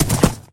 gallop1.mp3